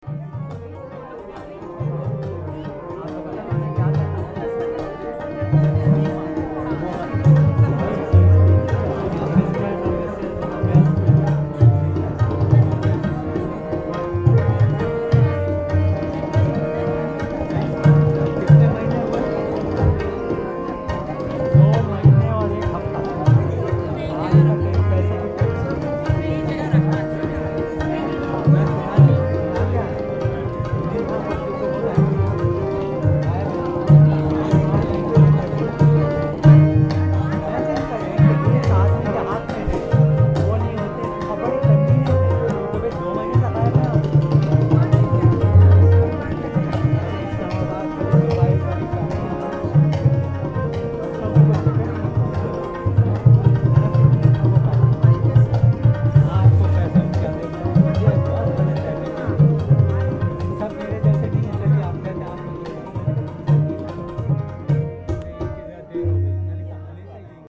harmonium
tabla
despite the high audience-noise